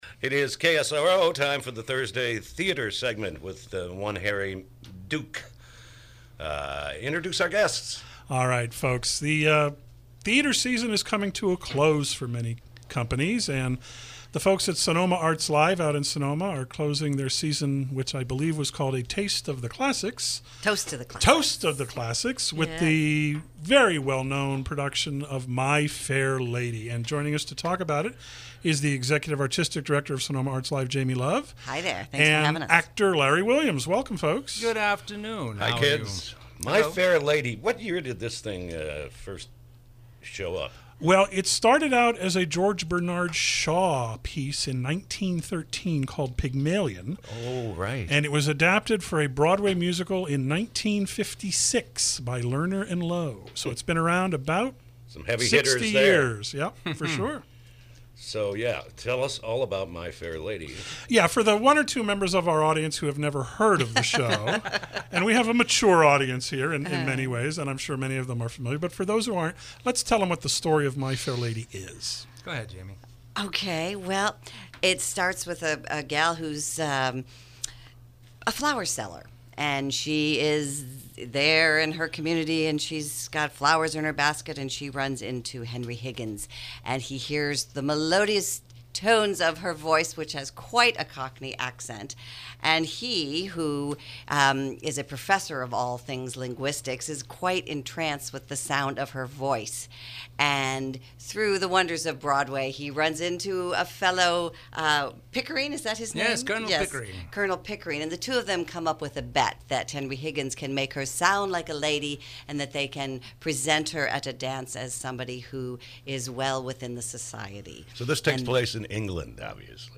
KSRO Interview: “My Fair Lady”